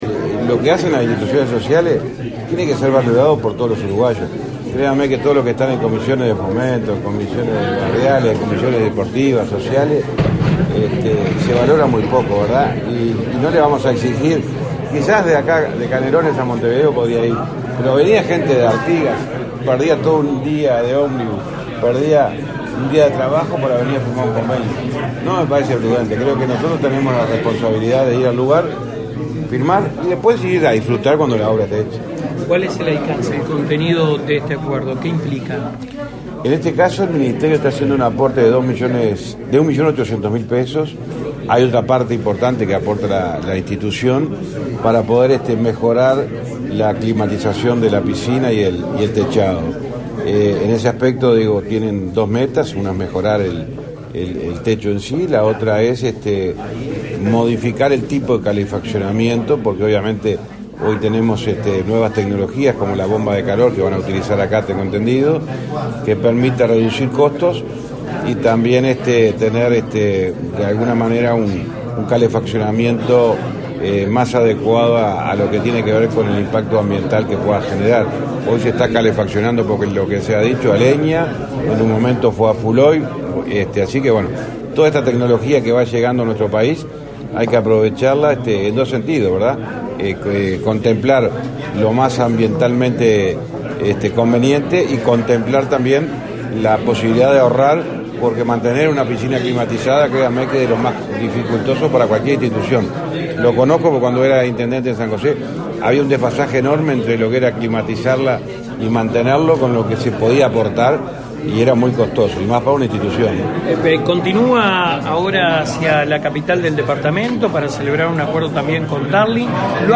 Declaraciones a la prensa del ministro de Transporte, José Luis Falero
Declaraciones a la prensa del ministro de Transporte, José Luis Falero 28/07/2023 Compartir Facebook X Copiar enlace WhatsApp LinkedIn Tras participar en la firma de un convenio con el Centro de Protección de Choferes de Pando, en el departamento de Canelones, este 28 de julio, el ministro de Transporte y Obras Públicas, José Luis Falero, realizó declaraciones a la prensa.